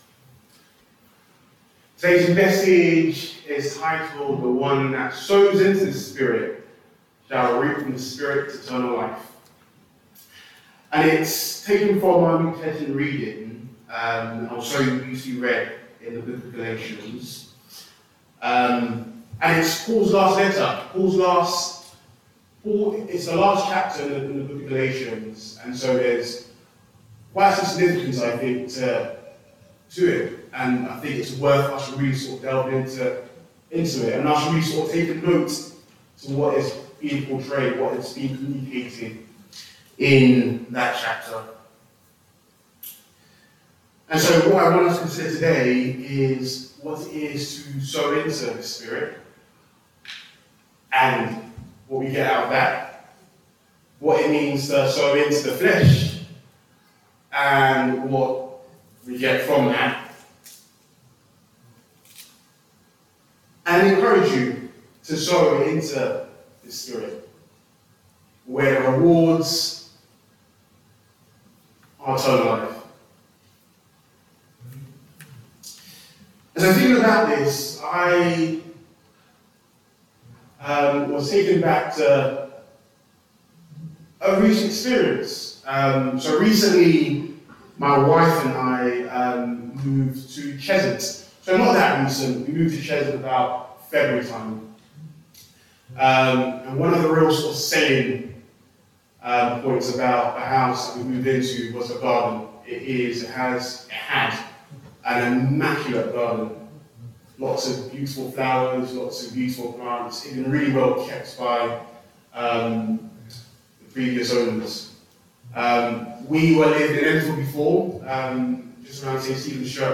SERMONS - Southgate Methodist Church